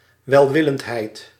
Ääntäminen
US
IPA : /ˈkaɪnd.nəs/